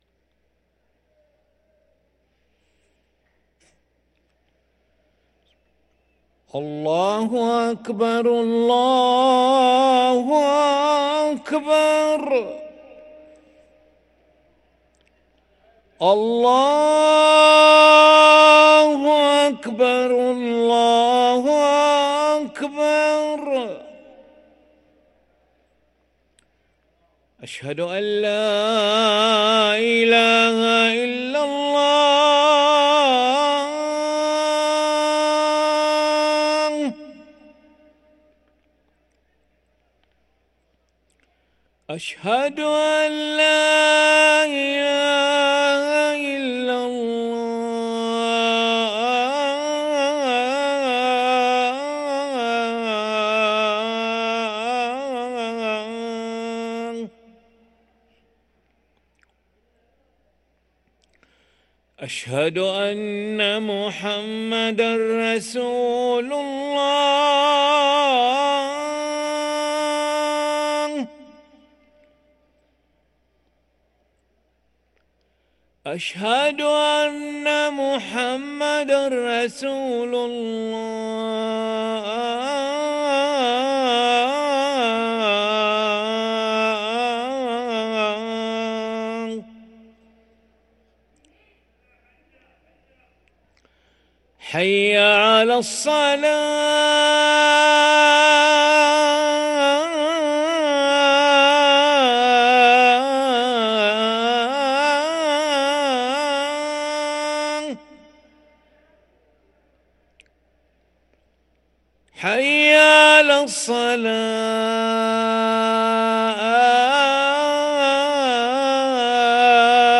أذان العشاء للمؤذن علي أحمد ملا الأحد 1 ذو القعدة 1444هـ > ١٤٤٤ 🕋 > ركن الأذان 🕋 > المزيد - تلاوات الحرمين